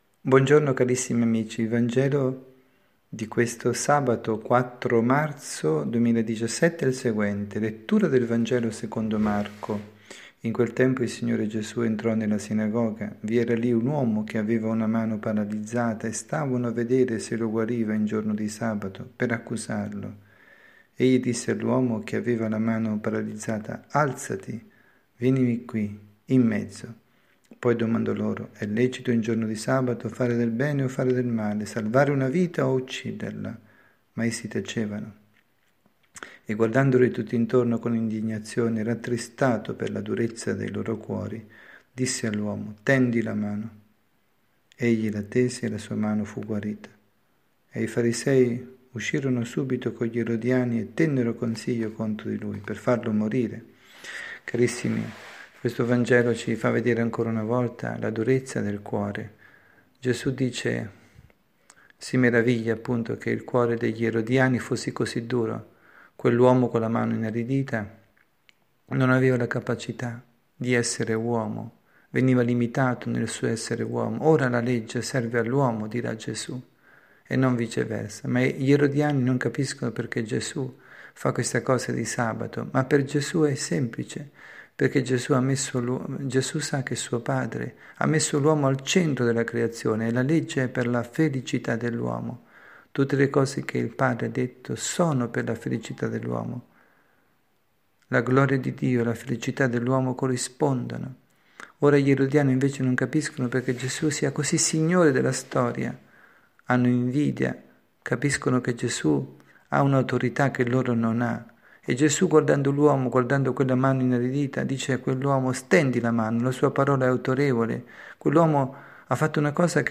Catechesi
dalla Parrocchia S. Rita, Milano